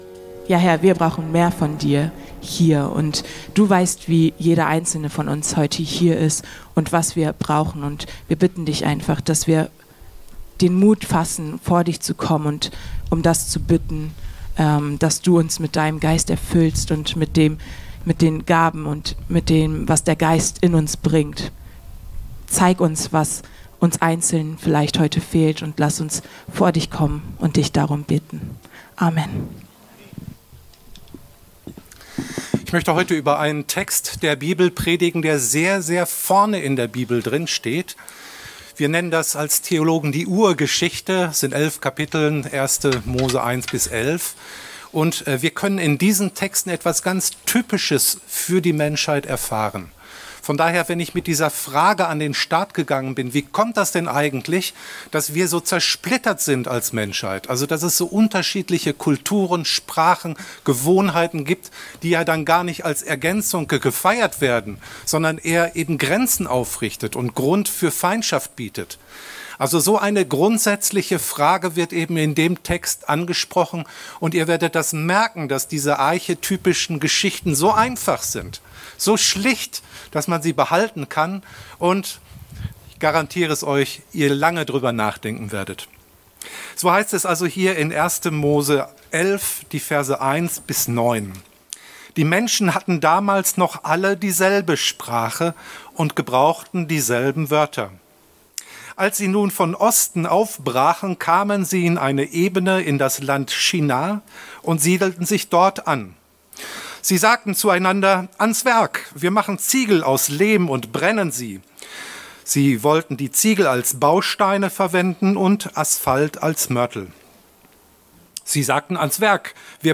02-Predigt.mp3